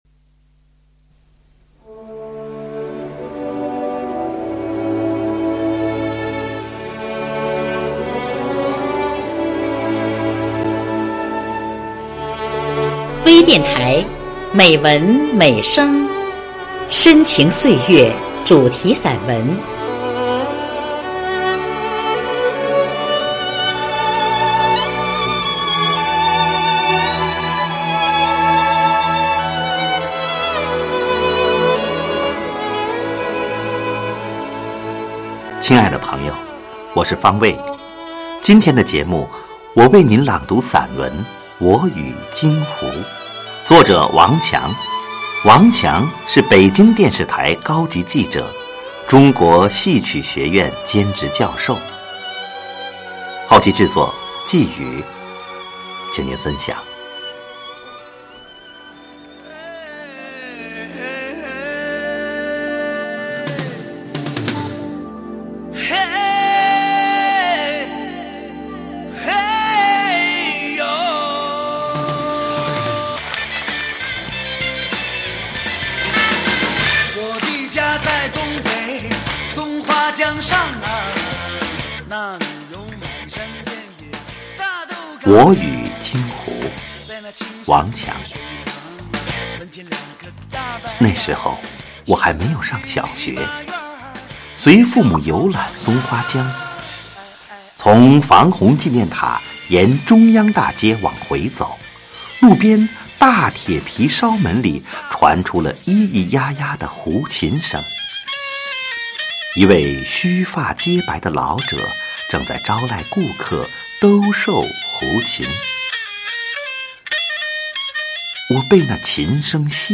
专业诵读